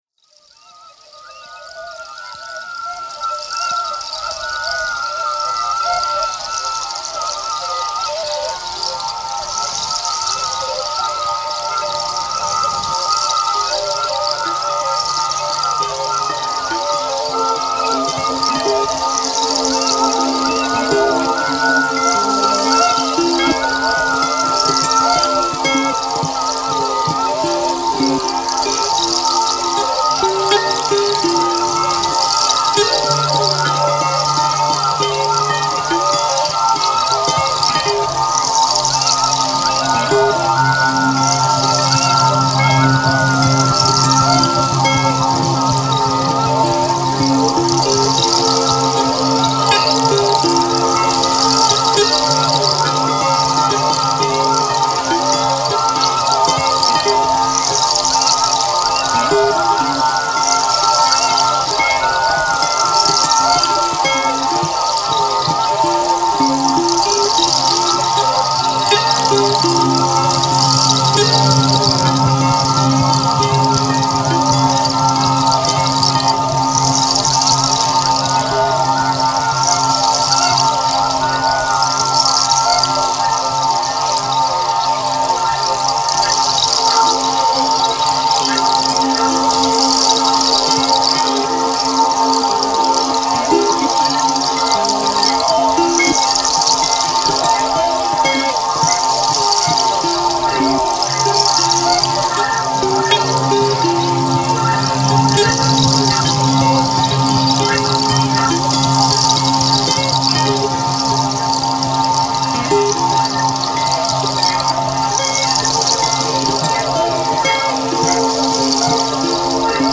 ethereal melodies